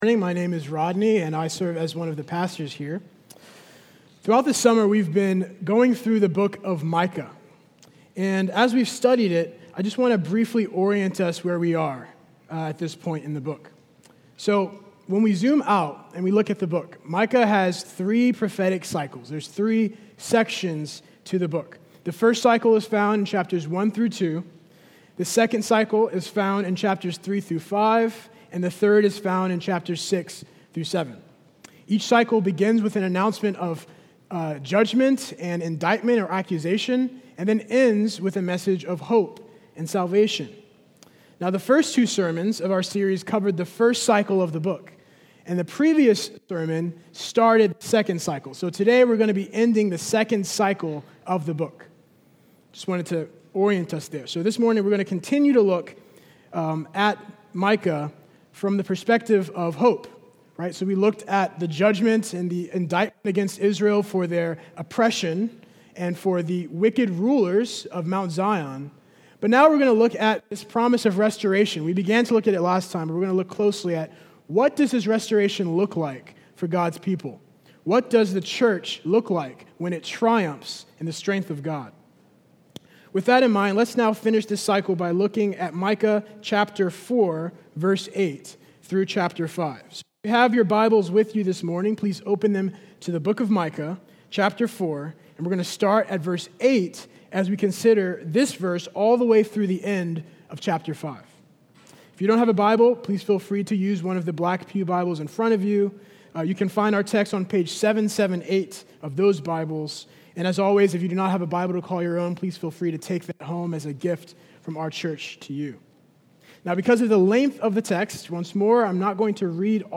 Sermon-824.mp3